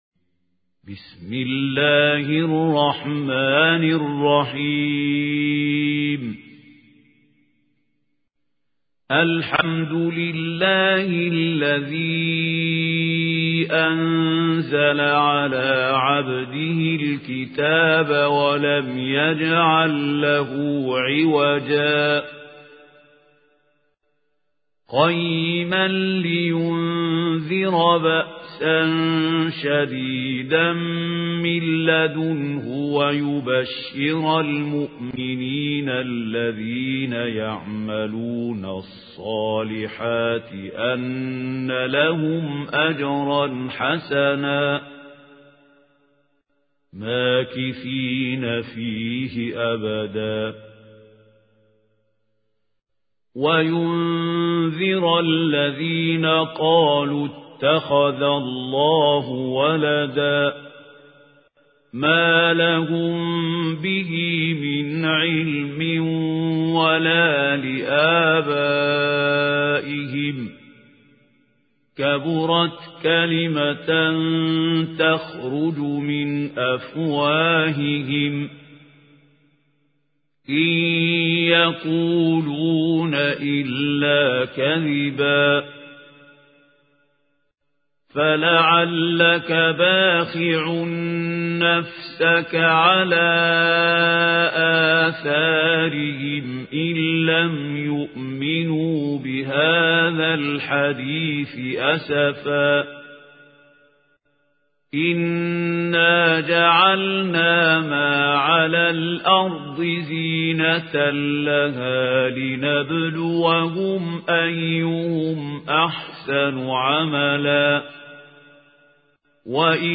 اسم التصنيف: المـكتبة الصــوتيه >> القرآن الكريم >> الشيخ خليل الحصري
القارئ: الشيخ خليل الحصري